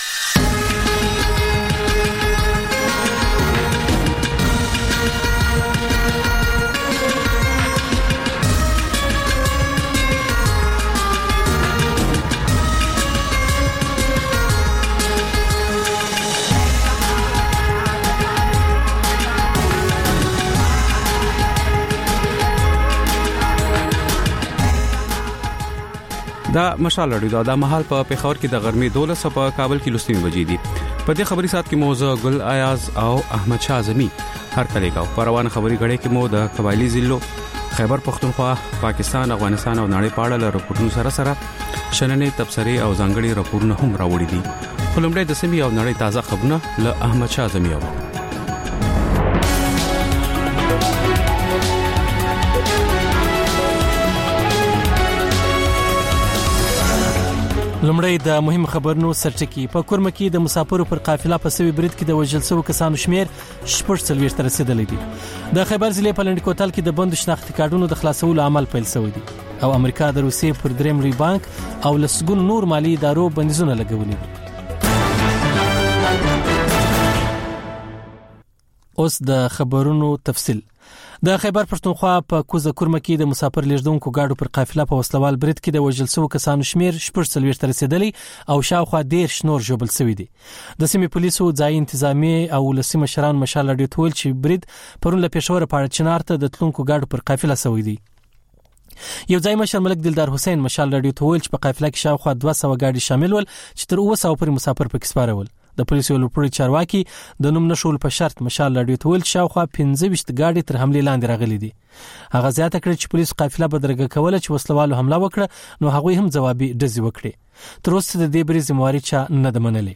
د مشال راډیو د ۱۴ ساعته خپرونو په لومړۍ خبري ګړۍ کې تازه خبرونه، رپورټونه، شننې، مرکې او کلتوري، فرهنګي رپورټونه خپرېږي.